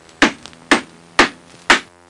Hammering Sound Effect
Download a high-quality hammering sound effect.
hammering.mp3